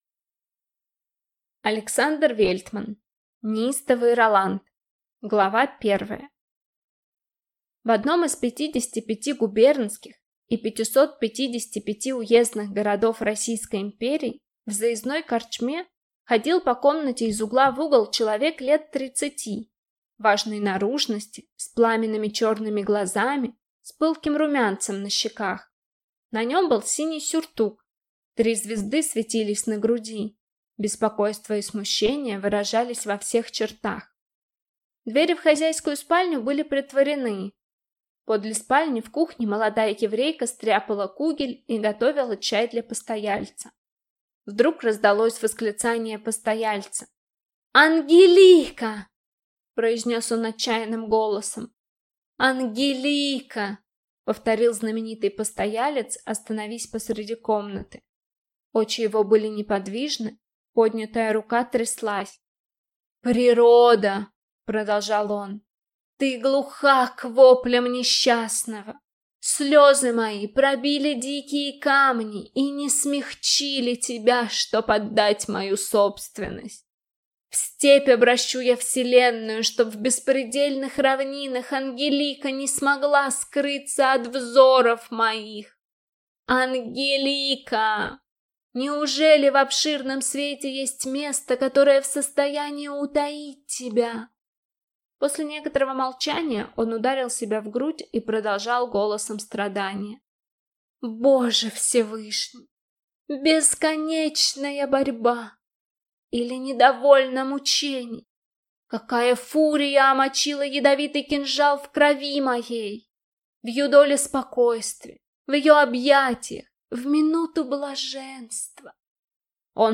Аудиокнига Неистовый Роланд | Библиотека аудиокниг